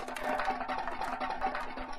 rolling_loop_2_sec.wav